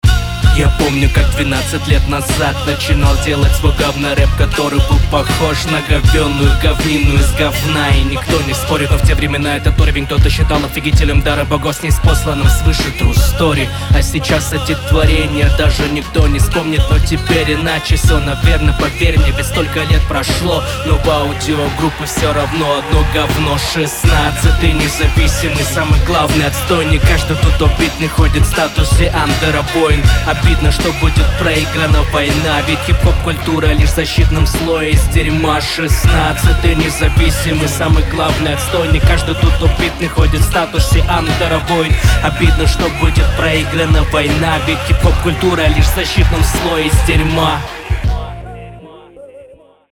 Вот если бы текст не был посвящен копротемам, возможно в этой ровной читке и можно было бы найти плюсы